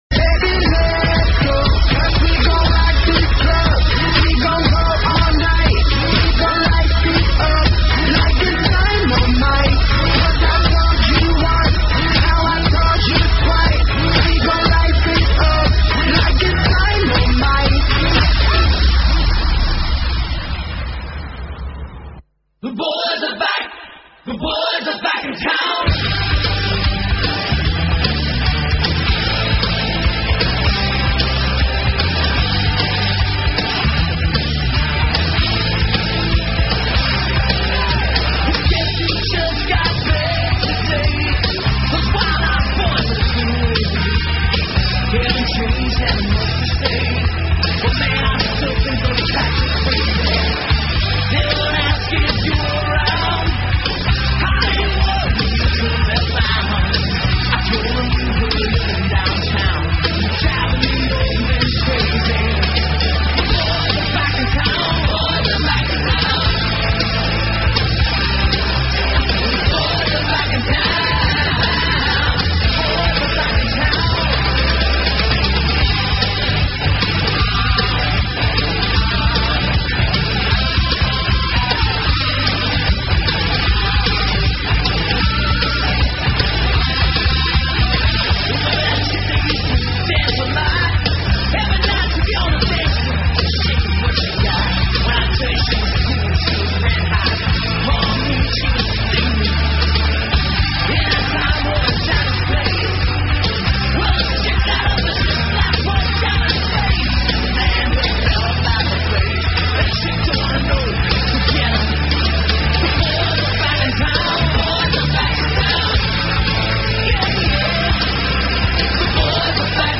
brand new tunes and pre-releases